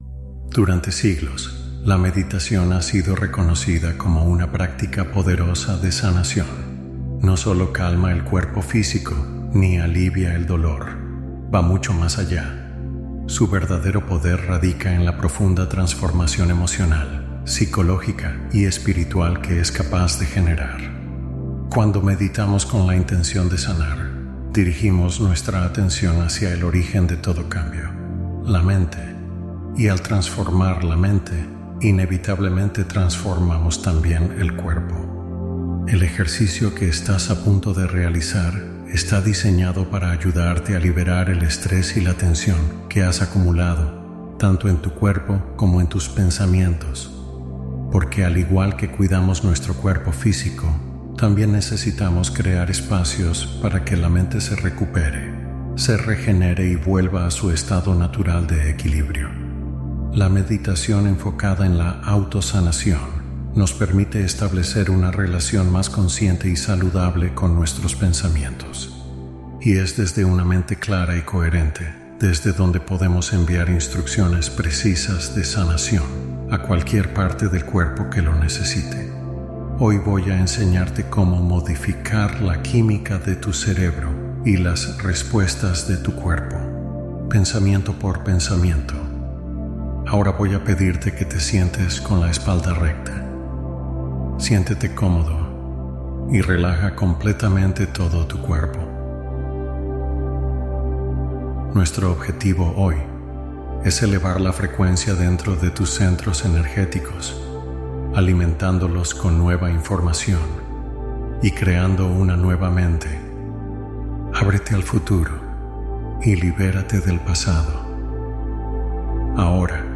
Meditación de la Mañana (20 Minutos) Para Atraer Un Día Milagroso